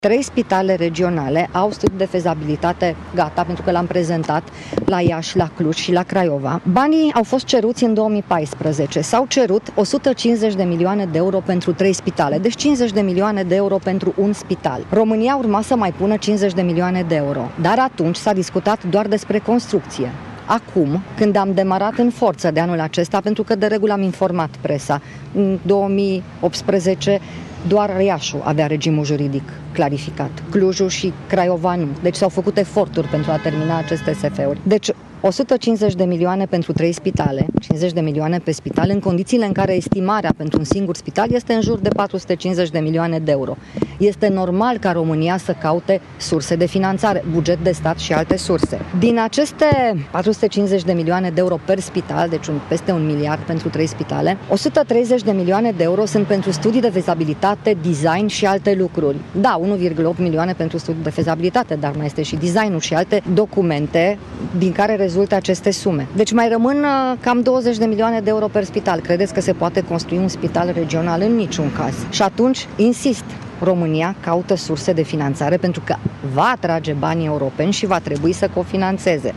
În ceea ce privește spitalele regionale care urmează să fie construite la Iași, Cluj și Craiova, ministrul Sorina Pintea a spus că România caută fonduri suplimentare pentru că fondurile europene sunt în valoare de 150 de milioane de euro în condițiile în care estimarea pentru un singur spital este de 450 de milioane de Euro: